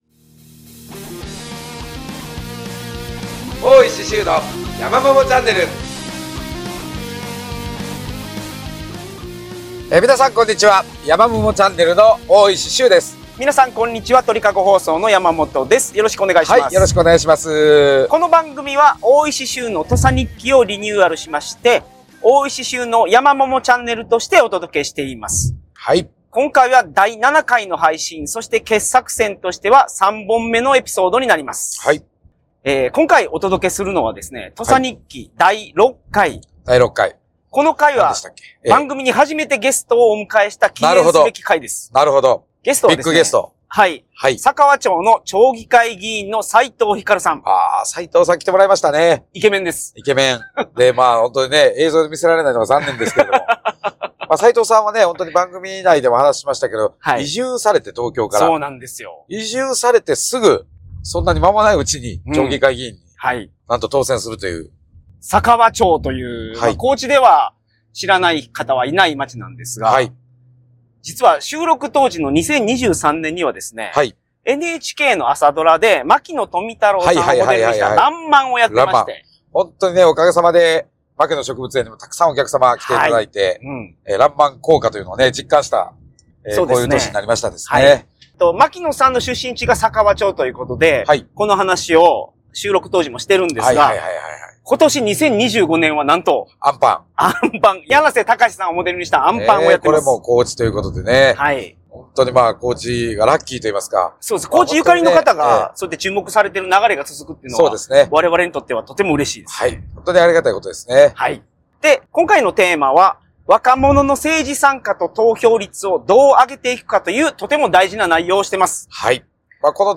今回は記念すべき土佐日記の初のゲスト回です。お迎えしたのは、高知県佐川町の町議会議員・斉藤ひかるさん。